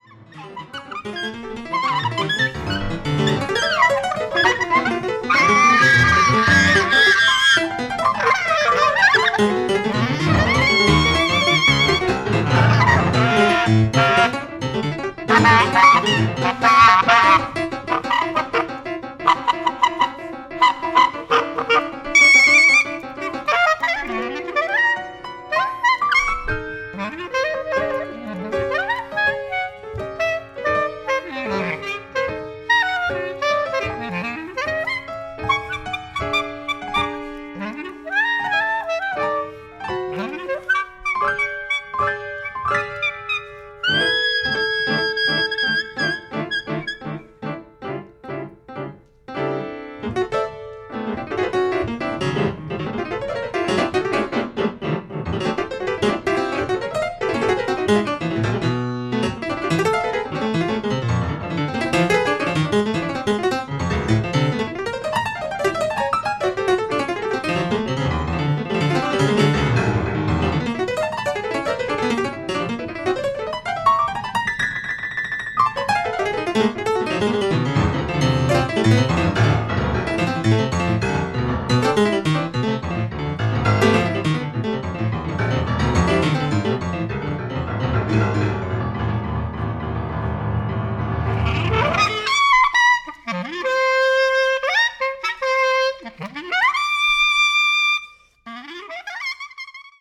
音色の妙からアンサンブルが立ち上る！
優れた音色で絶妙なアンサンブルを聴かせてくれるので、フリージャズによくある閉塞感を全く感じさせませんね！